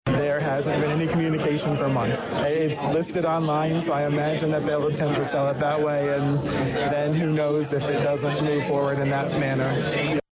Mayor Williams speaks during Tuesday morning Fire and Police Department swearing in ceremonies.